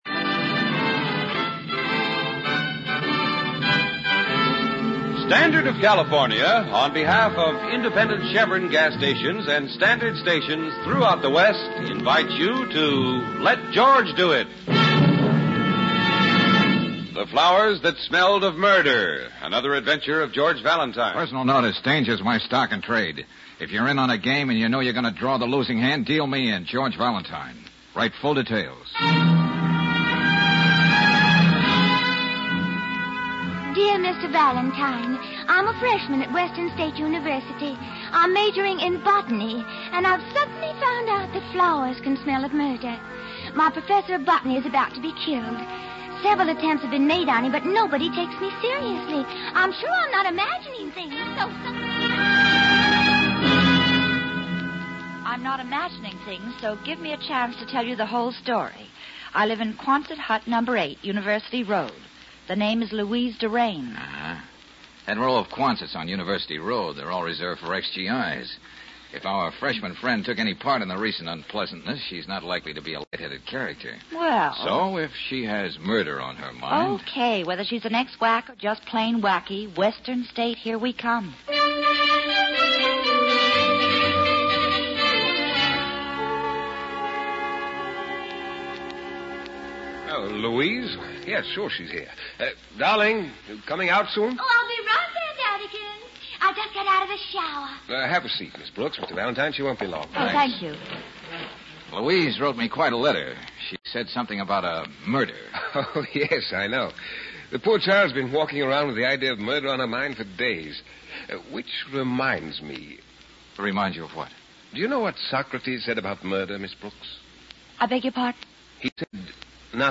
Let George Do It Radio Program
The Flowers that Smelled of Murder, starring Bob Bailey